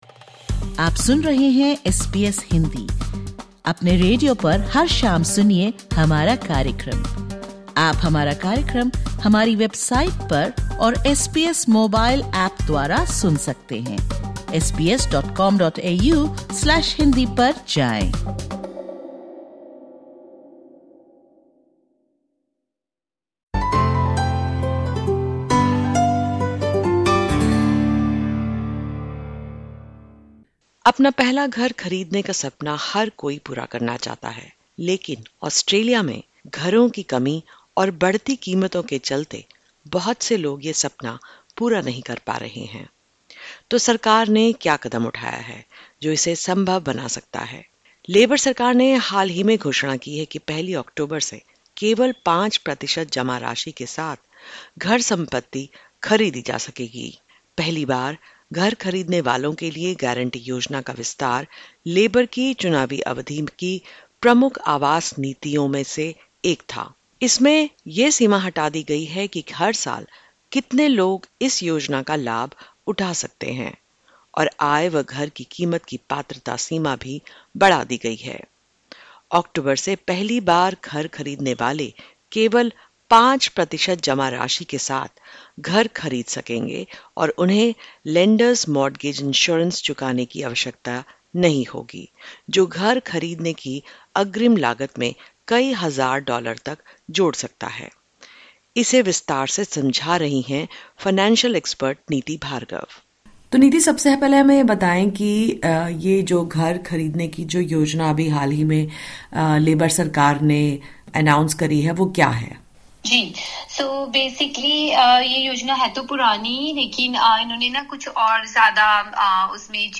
(Disclaimer: The information given in this interview is of a general nature.